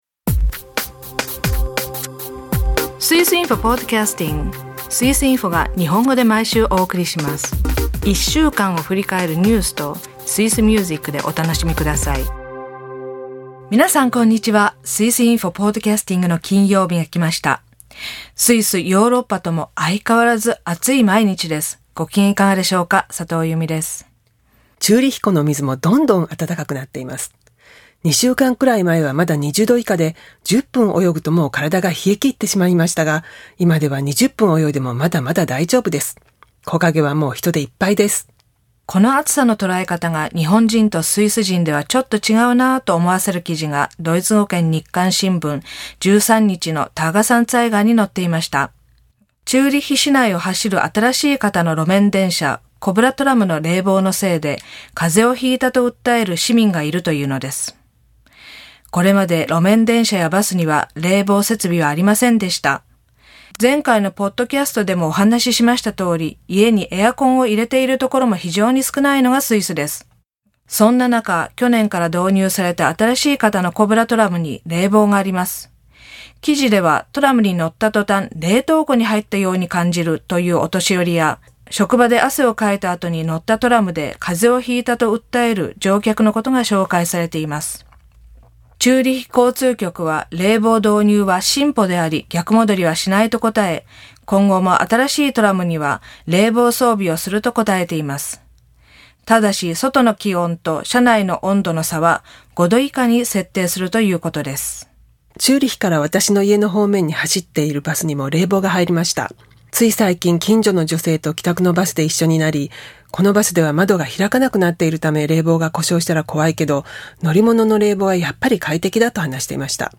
著名映画監督のロマン・ポランスキー氏が釈放されました。歌はフランス語のヒップホップ「Le vent tourne」を。朗読では、ウーリがとうとうドイツの傭兵になります。